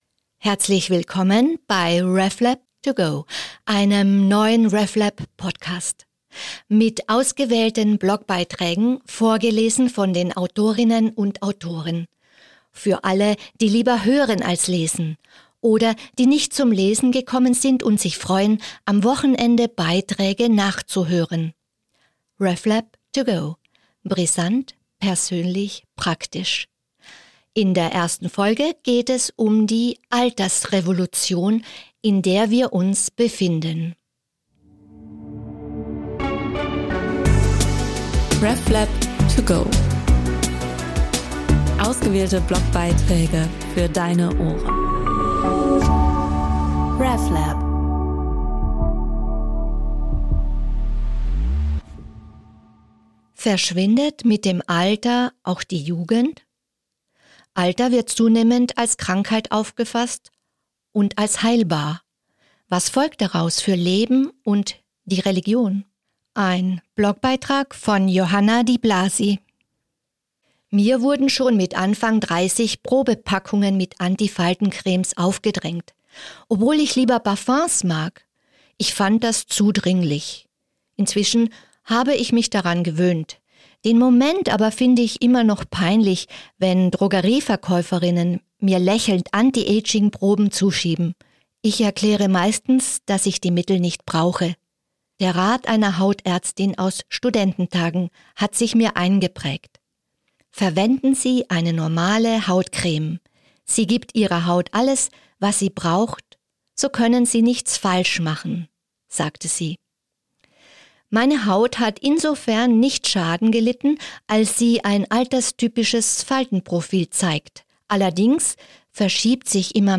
Alter wird zunehmend als Krankheit aufgefasst – und als heilbar. Was folgt daraus fürs Leben und die Religion? Geschrieben und vorgelesen von